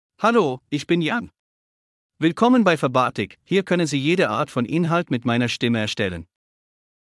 Jan — Male German AI voice
Jan is a male AI voice for German (Switzerland).
Voice sample
Male
Jan delivers clear pronunciation with authentic Switzerland German intonation, making your content sound professionally produced.